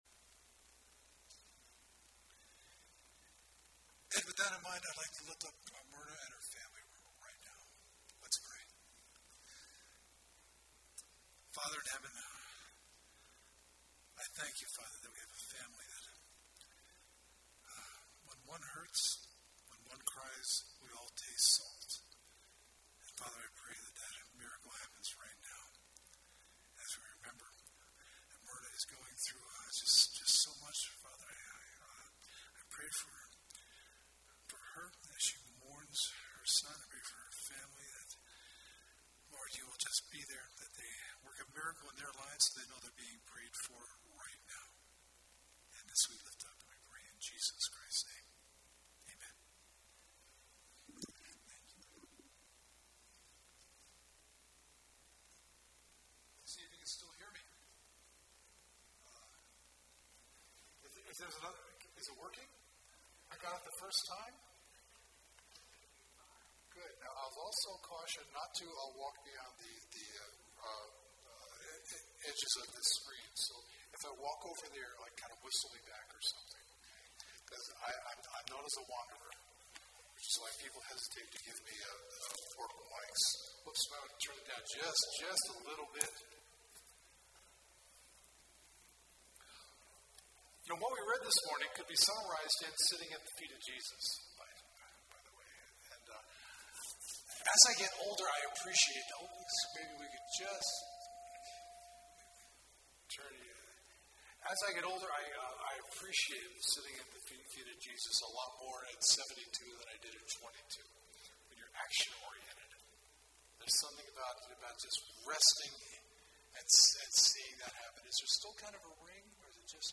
Audio Sermon
Sabbath Sermon